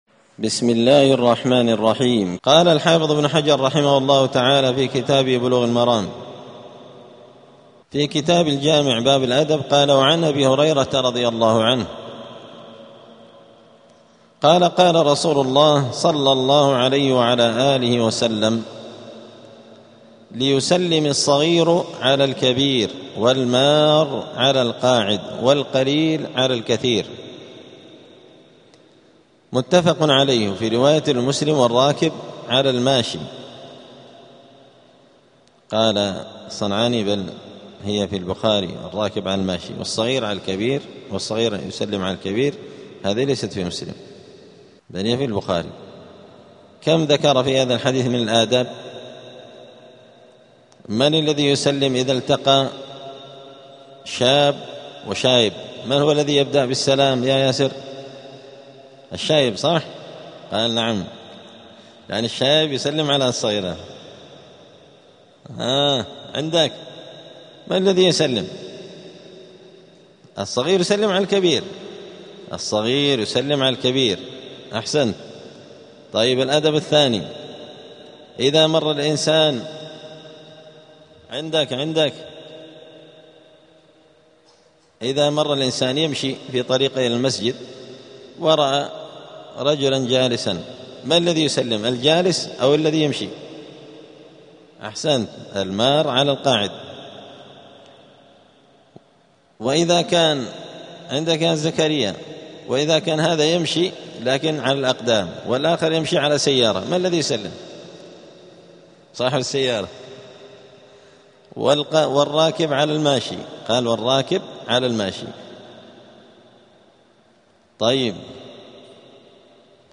*الدرس السادس (6) {باب اﺑﺘﺪاء اﻟﺴﻼﻡ ﻣﻦ اﻟﺼﻐﻴﺮ ﻋﻠﻰ اﻟﻜﺒﻴﺮ}*
دار الحديث السلفية بمسجد الفرقان قشن المهرة اليمن